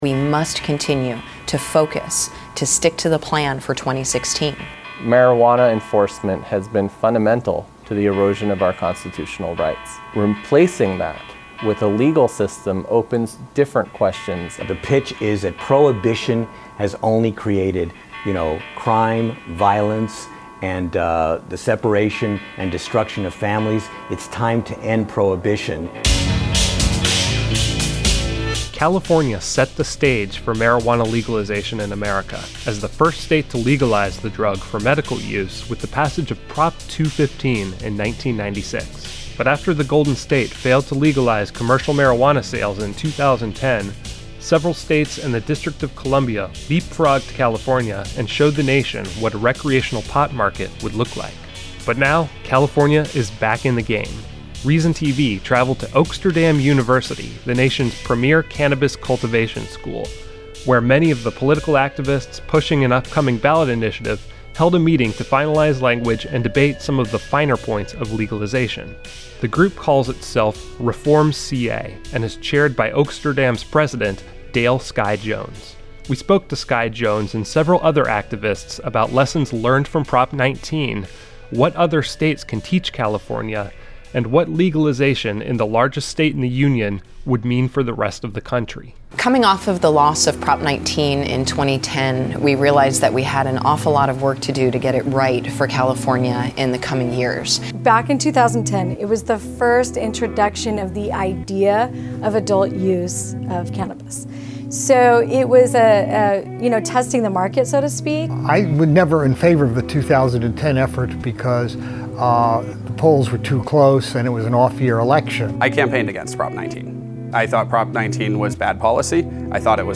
Reason TV travelled to Oaksterdam University, the nation's premier cannabis cultivation school, where many of the political activists pushing an upcoming ballot initiative held a meeting to finalize language and debate some of the finer points of legalization.